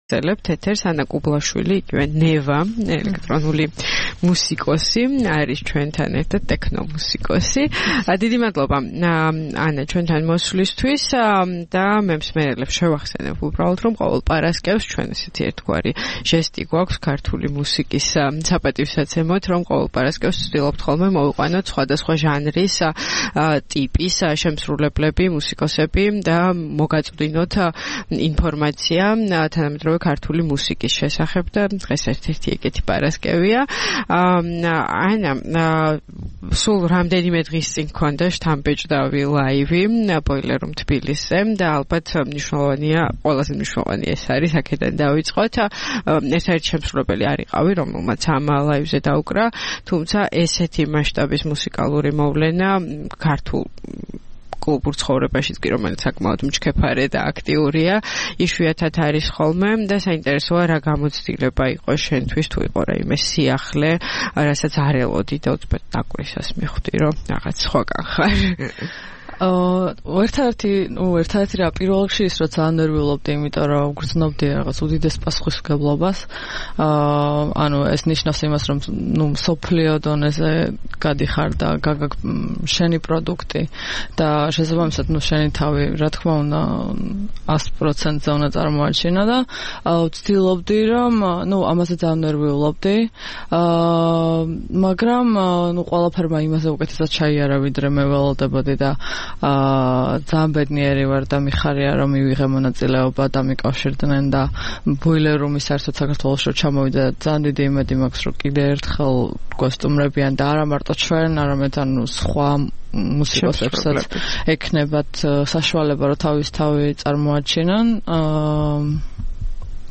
მელოდიური ტექნო